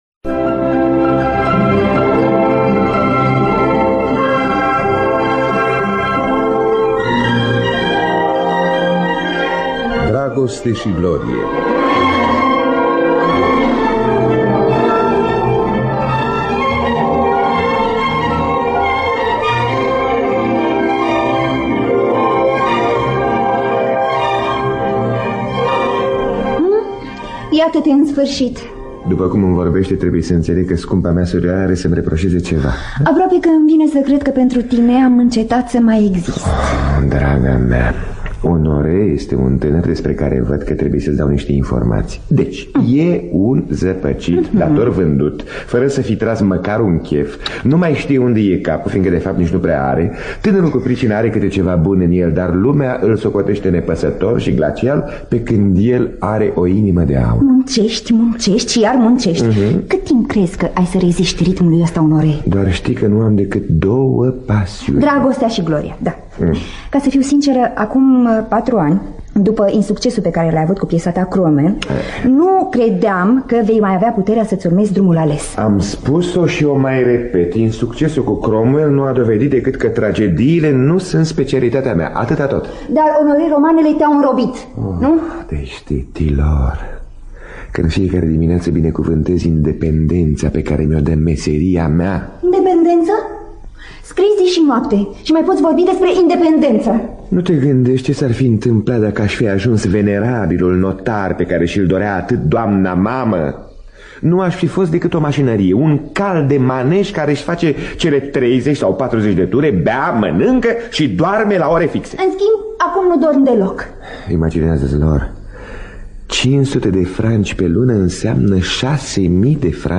Biografii, memorii: Dragoste și glorie – Corespondența lui Honoré de Balzac. Scenariu radiofonic de Maria Cordoneanu.